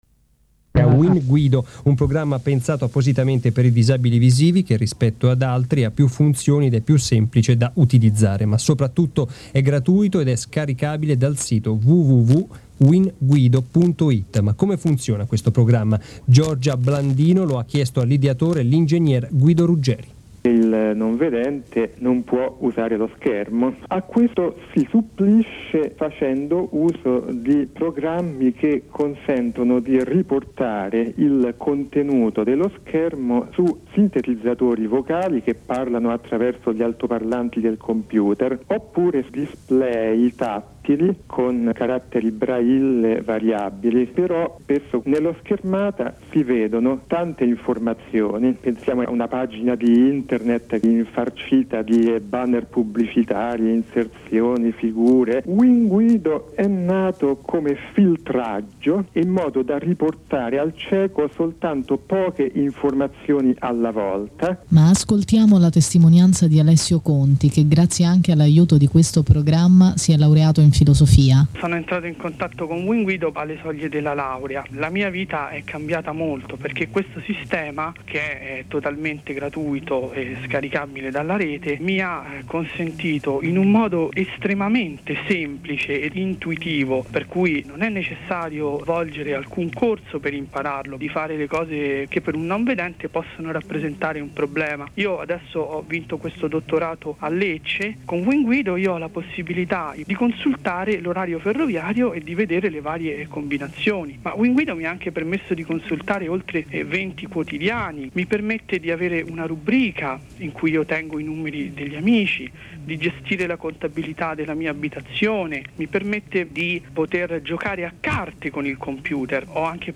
Quello di ieri sera, comunque, era solo un breve estratto dell'intervista, che è stato inserito nel Radiogiornale.